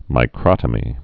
(mī-krŏtə-mē)